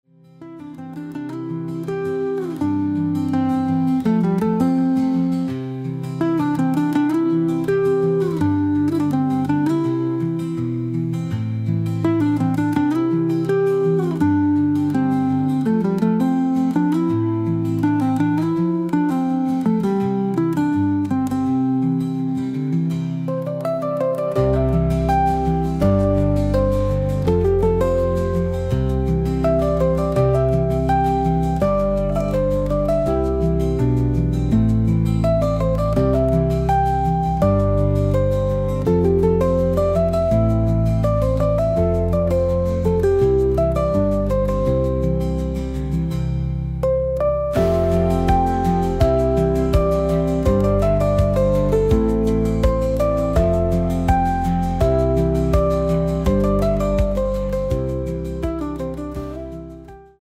Winterfeeling
Musik